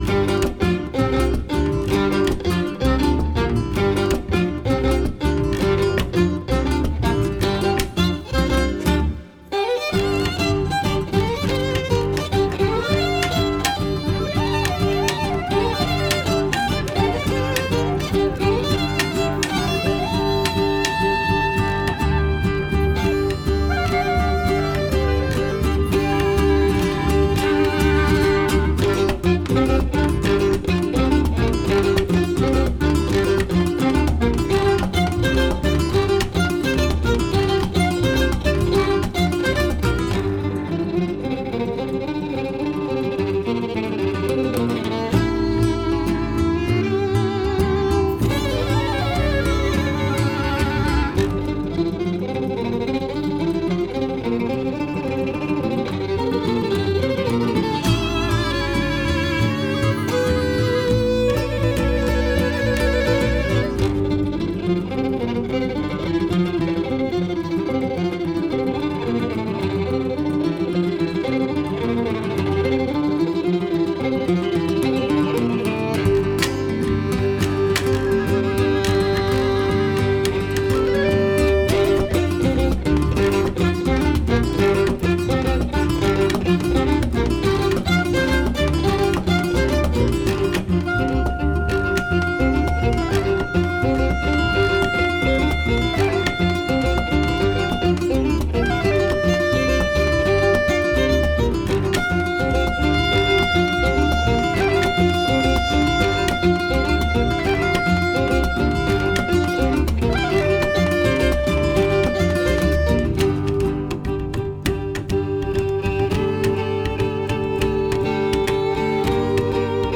with an acoustic quartet live